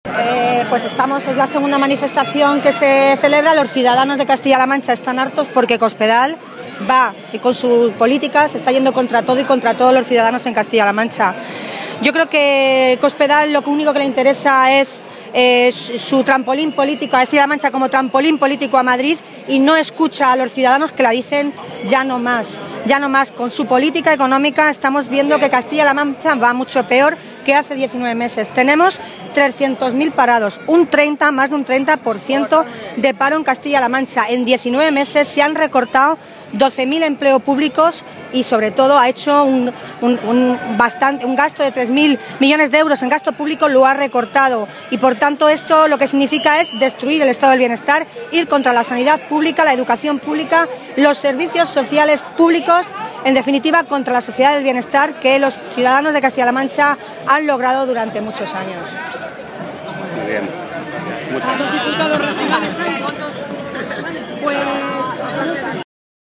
Tolón hacía estas manifestaciones mientras participaba en la manifestación convocada hoy en la capital regional por hasta 8 centrales sindicales y que bajo el lema “En defensa de los servicios públicos”, ha protestado contar los recortes en los servicios públicos y los despidos del Gobierno regional.
Cortes de audio de la rueda de prensa